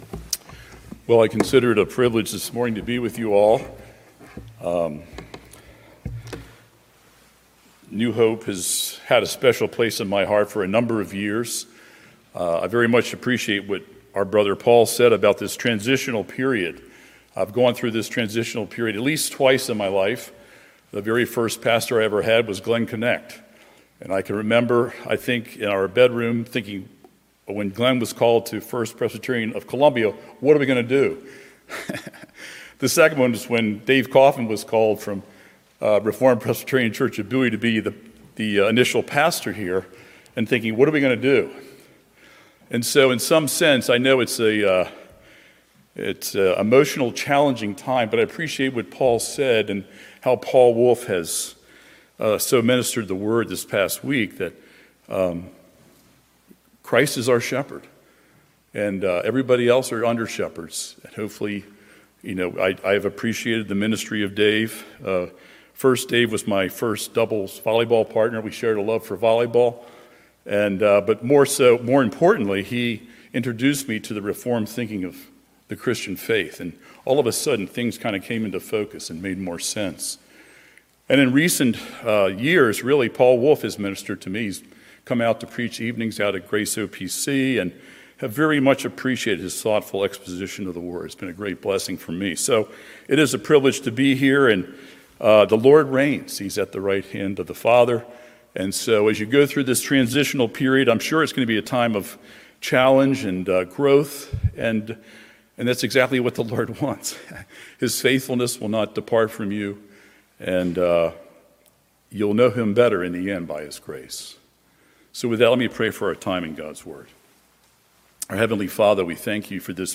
A Tale of Two Sisters: Sermon on Luke 10:38-42 - New Hope Presbyterian Church